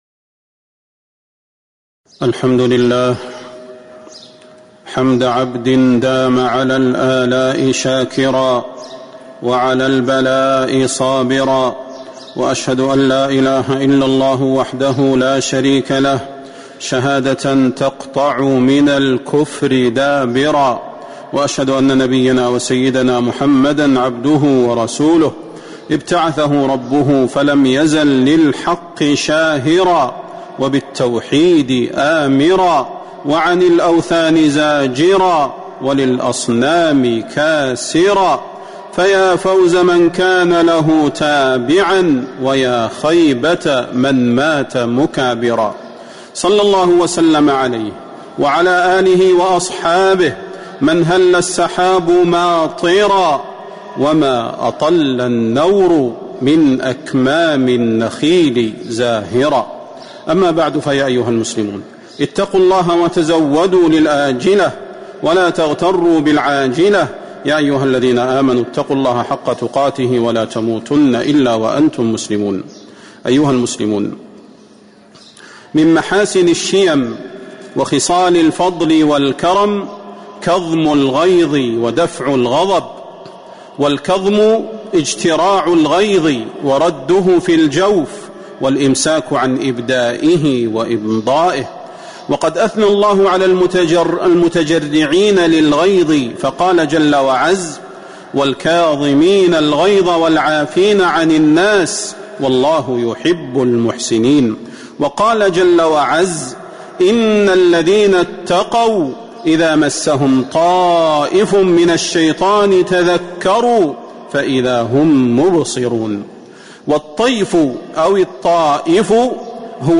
تاريخ النشر ٤ شعبان ١٤٤٤ هـ المكان: المسجد النبوي الشيخ: فضيلة الشيخ د. صلاح بن محمد البدير فضيلة الشيخ د. صلاح بن محمد البدير والكاظمين الغيظ